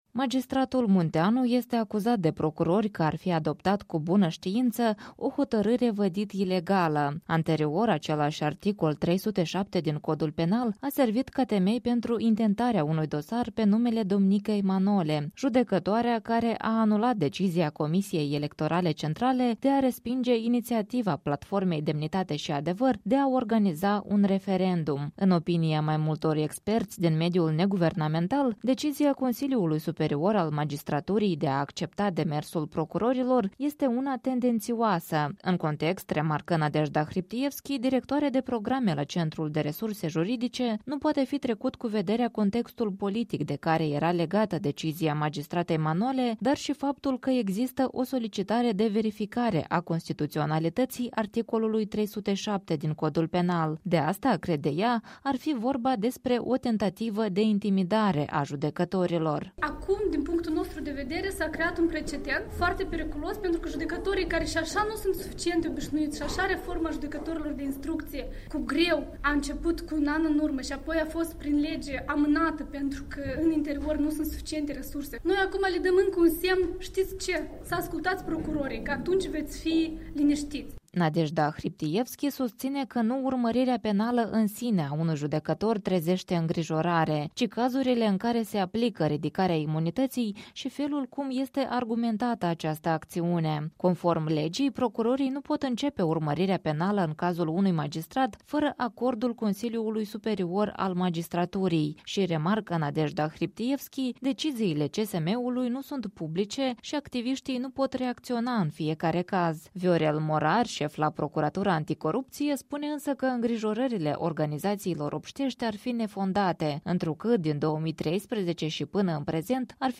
Clubului Jurnaliștilor de Investigație a luat în dezbatere situația în care, cu acordul Consiliului Superior al Magistraturii este pornită urmărirea penală în privința unui judecător.
Prezent la dezbateri, membrul Consiliului Superior al Magistraturii, Teodor Cârnaț a spus că statutul de judecător nu trebuie să fie un paravan în fața unor decizii, ilegalitatea cărora trebuie să o dovedească procurorii: